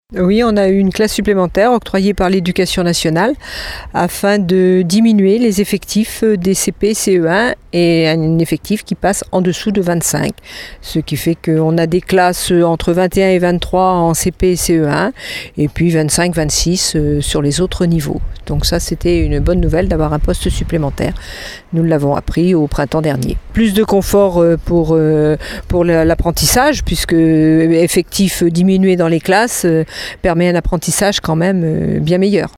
Catherine Prévos devant les grilles de l’école élémentaire Plaisance.
Et cette année, une classe supplémentaire a pu voir le jour. De quoi soulager les 18 classes que compte l’établissement, avec une meilleure répartition des élèves, comme le souligne Catherine Prévos, adjointe au maire en charge des affaires scolaires :